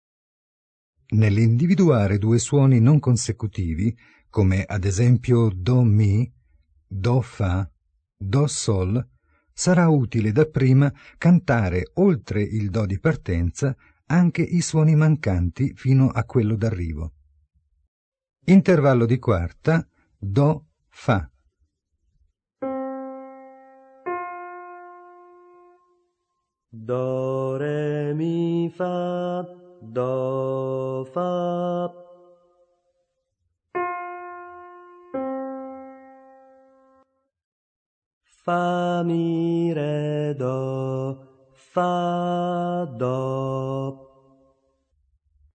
Ascoltate i consigli del Maestro e poi cantate insieme allo studente.